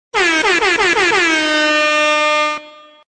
AIR HORN DUUUUUUUUUUUUUUU